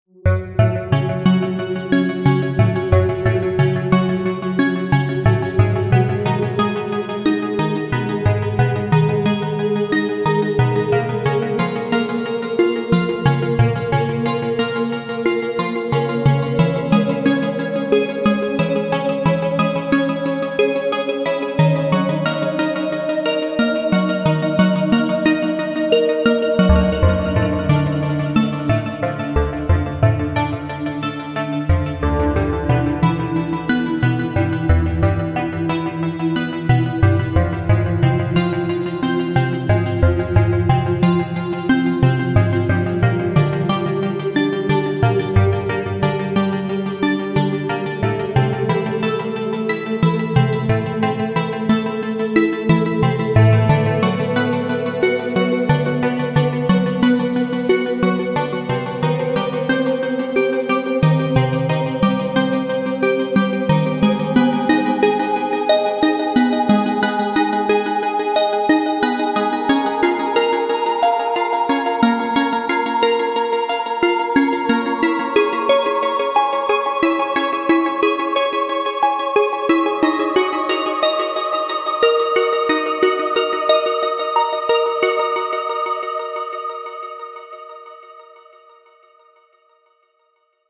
• Качество: 320, Stereo
Electronic
спокойные
без слов
космическая музыка
electro house